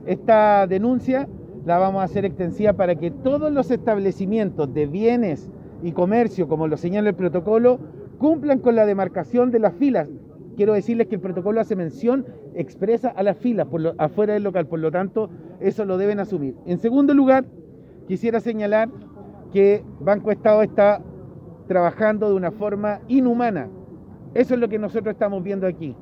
Por su parte, el presidente del Consejo Regional de La Araucanía, Alejandro Mondaca, dijo que están solicitando ampliar la protección para las personas que hacen largas filas en otras entidades financieras, cajas de pago o sucursales de las Administradoras de Fondos de Pensiones.